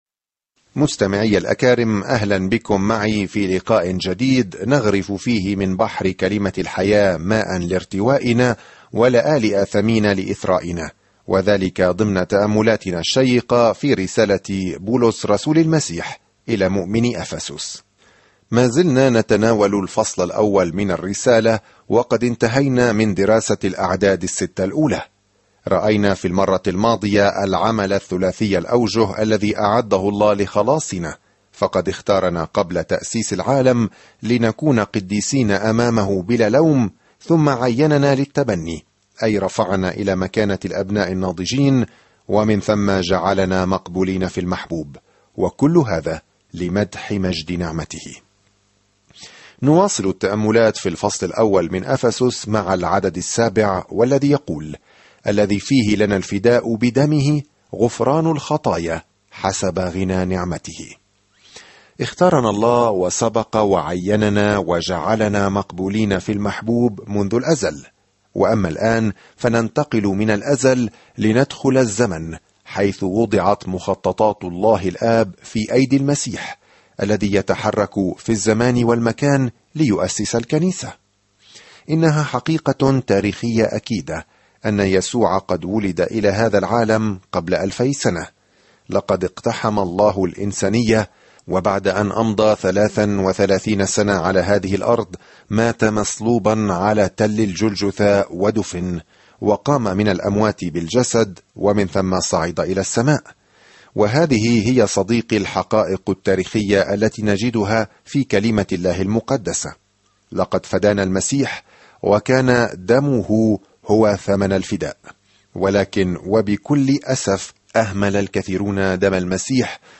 الكلمة أَفَسُسَ 7:1 يوم 5 ابدأ هذه الخطة يوم 7 عن هذه الخطة من الأعالي الجميلة لما يريده الله لأولاده، تشرح الرسالة إلى أهل أفسس كيفية السلوك في نعمة الله وسلامه ومحبته. سافر يوميًا عبر رسالة أفسس وأنت تستمع إلى الدراسة الصوتية وتقرأ آيات مختارة من كلمة الله.